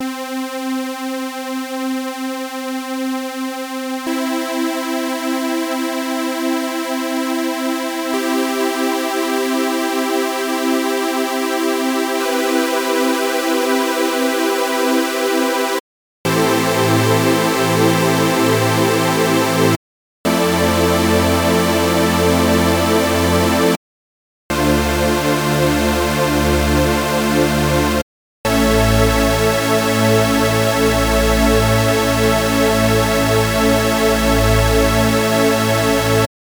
В Serum есть режимы унисона, по умолчанию стоит Linear, который ближе к Мэссиву, ритмично вибрирует как хаммонд какой нибудь. И есть Super, который менее предсказуем, он ближе к Вирусу: Linear Super Вложения Super.mp3 Super.mp3 1,4 MB · Просмотры: 813 Linear.mp3 Linear.mp3 1,4 MB · Просмотры: 802